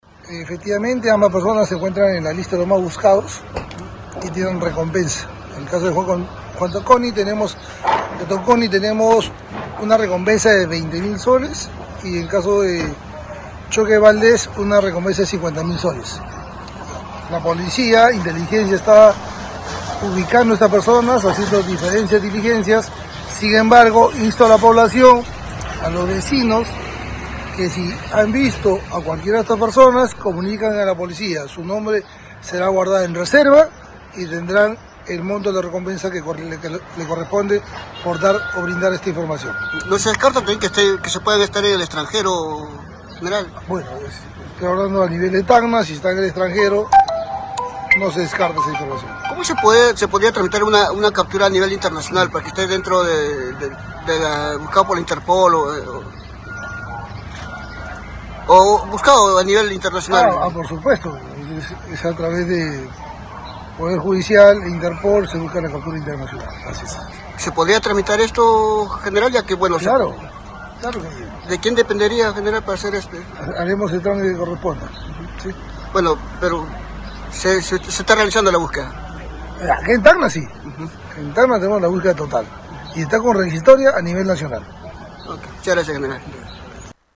En entrevista con el general PNP Arturo Valverde, jefe de la Región Policial